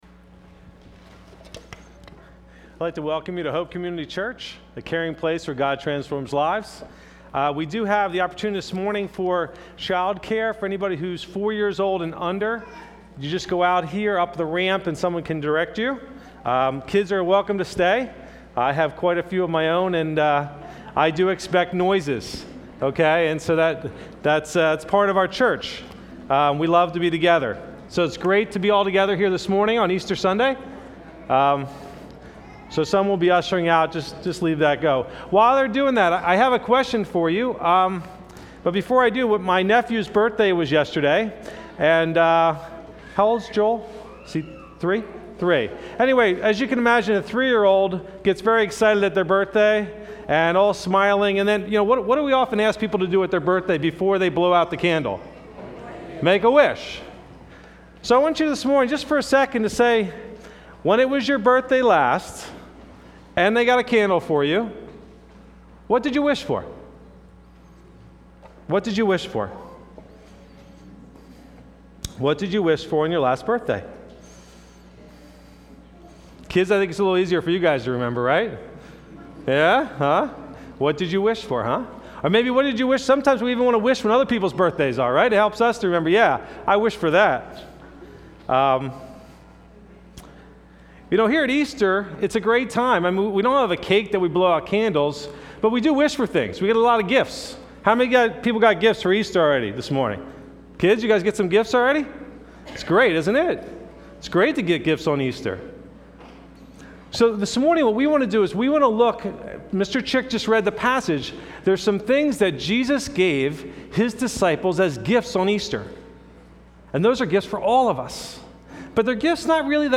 Occasion: Easter Sunday